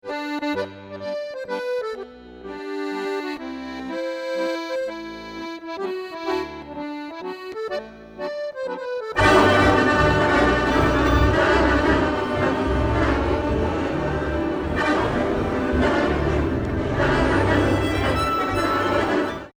for accordion and computer